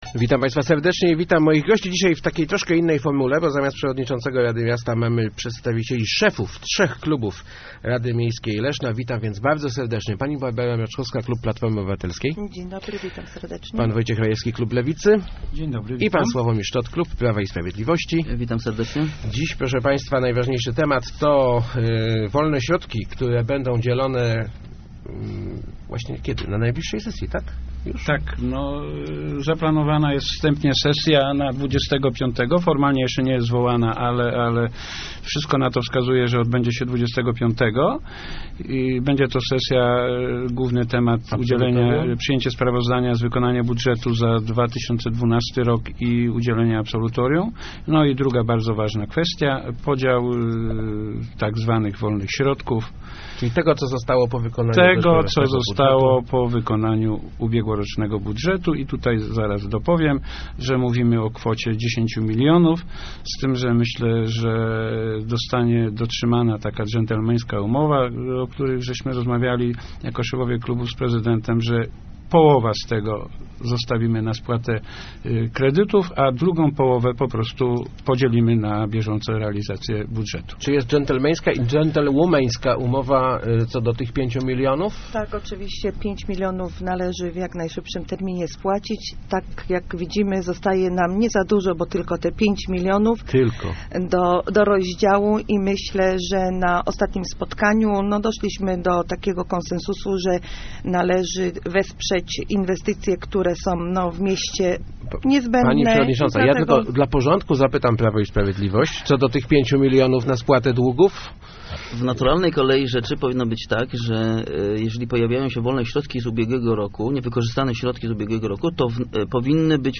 Gośćmi Kwadransa byli szefowie klubów Rady Miejskiej Leszna: Barbara Mroczkowska(PO), Wojciech Rajewski (Lewica) i Sławomir Szczot (PiS).